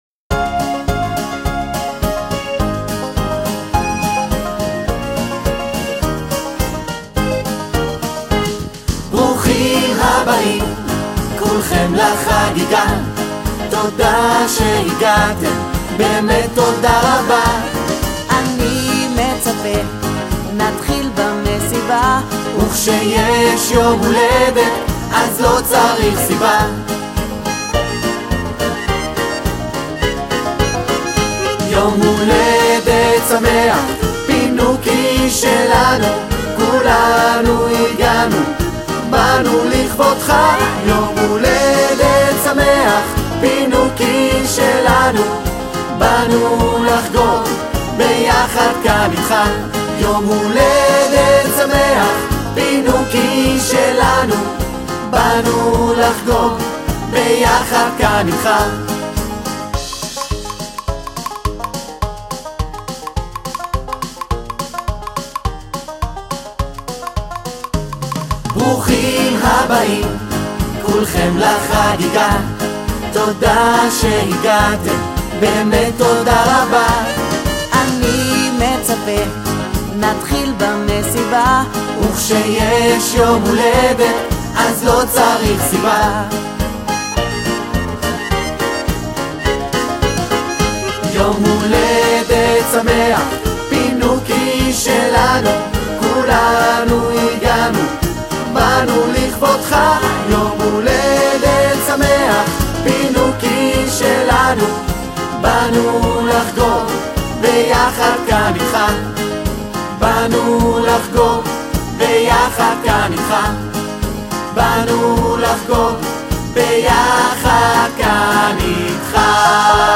מבחר שירים מתוך ההצגה :